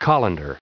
889_colander.ogg